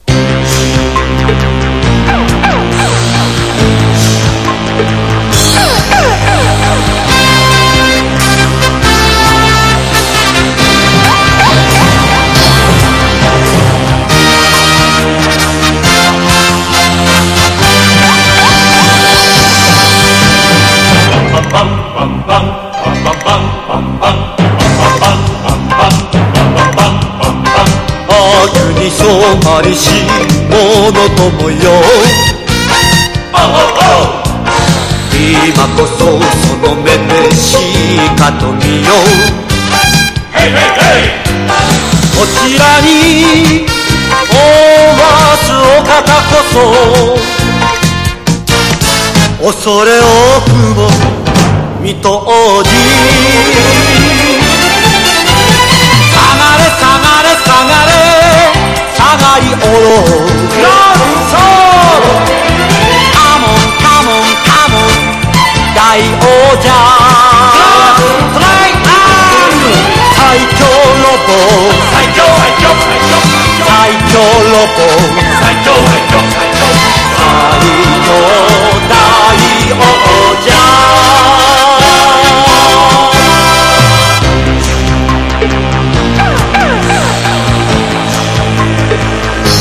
和モノ / ポピュラー# SOUNDTRACK / MONDO